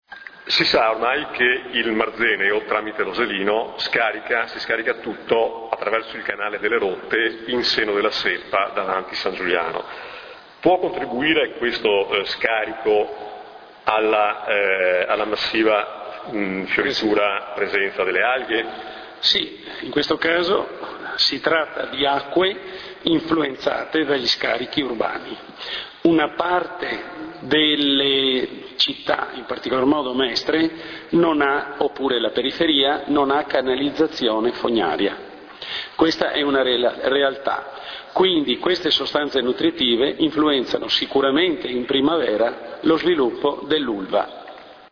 L'intervista           [... attiva l'audio, file .mp3]